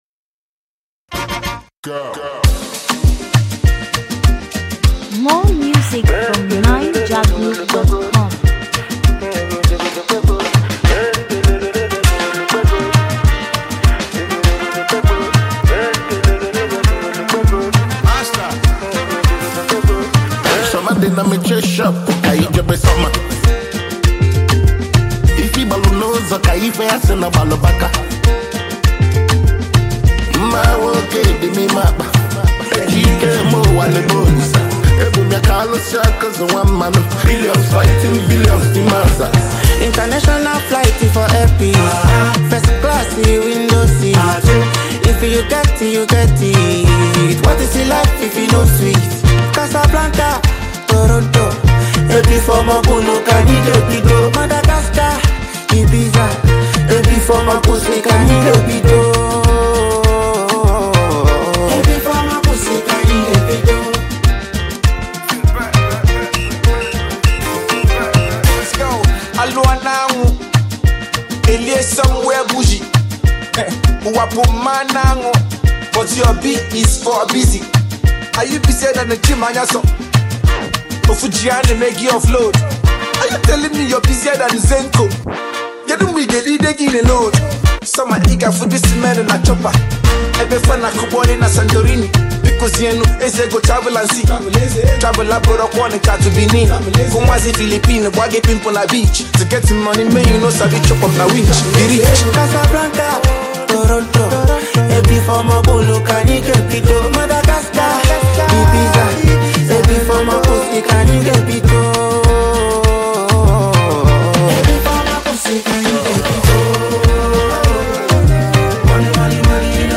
Naija-music